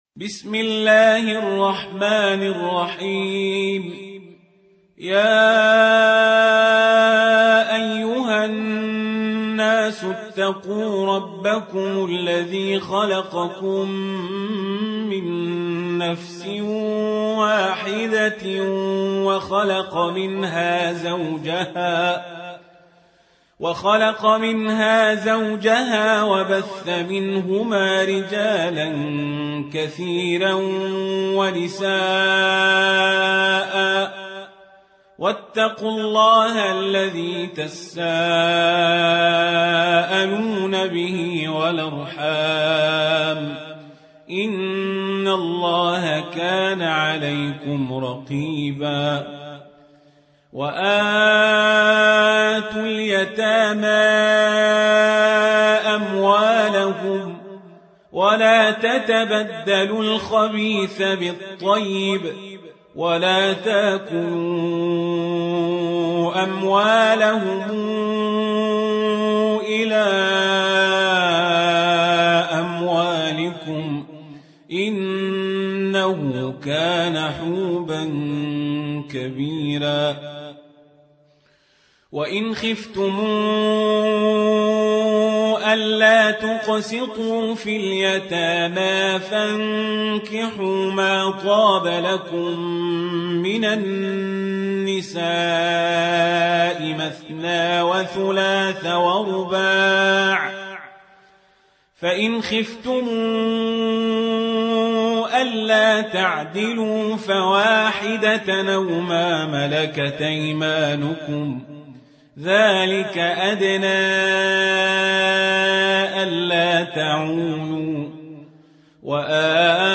تحميل : 4. سورة النساء / القارئ القزابري / القرآن الكريم / موقع يا حسين